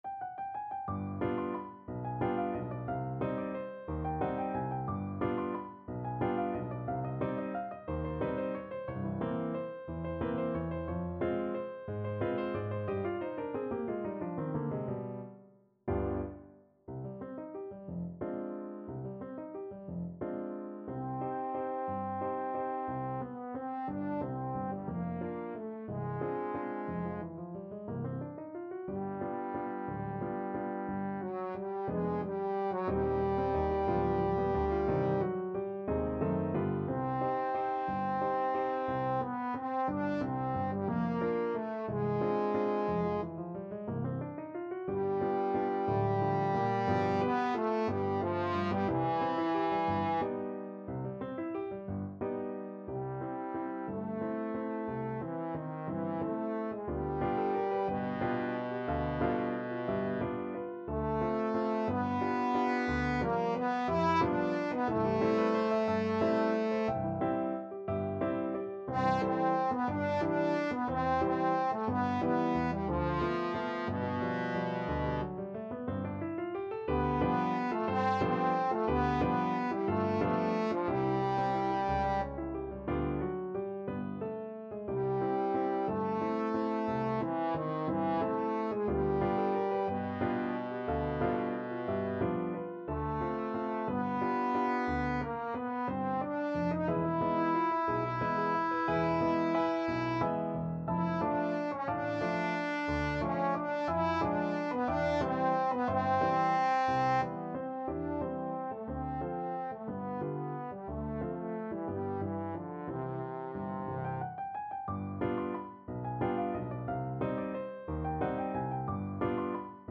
Allegro movido =180 (View more music marked Allegro)
3/4 (View more 3/4 Music)
Classical (View more Classical Trombone Music)
Mexican